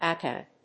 音節acad.